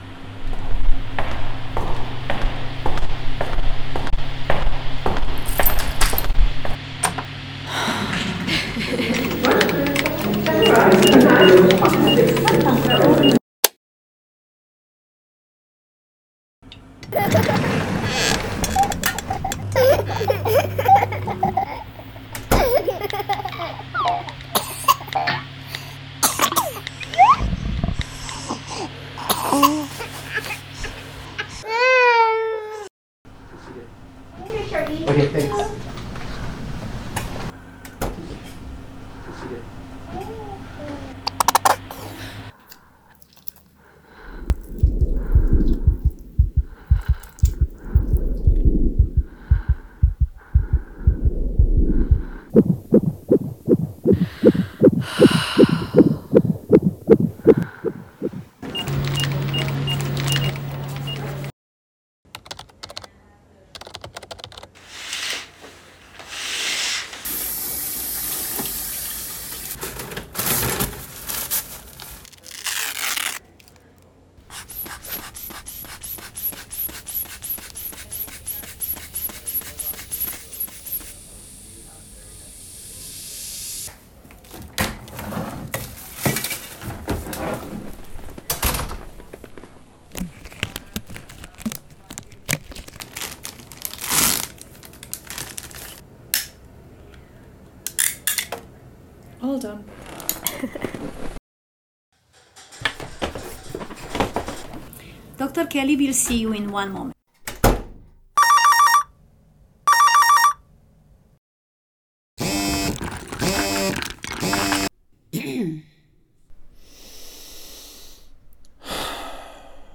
There is a hum and drum to the clinical day, sounds and rhythms that pervade physician and patient’s soundscape.
The soundtrack of the daily grind is experienced as an audio blanket of white noise.
Soundtrack-of-clinic-day.m4a